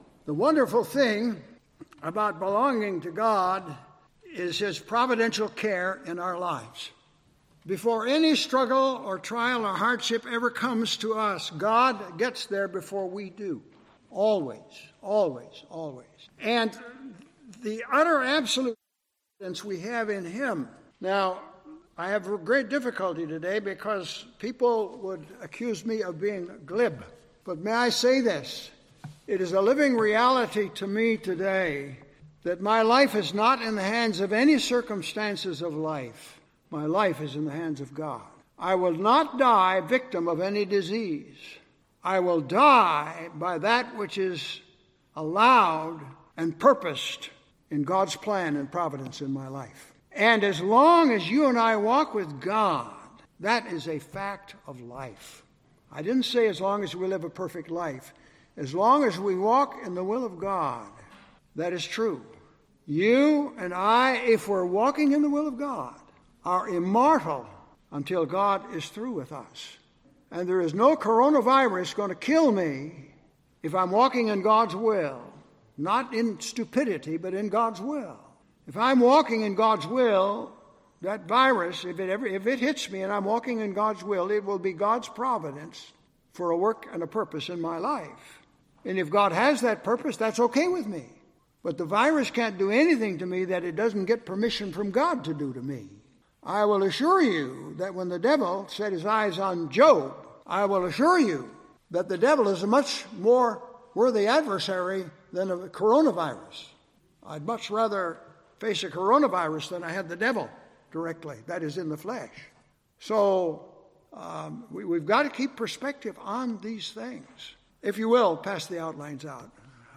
Genre Sermon or written equivalent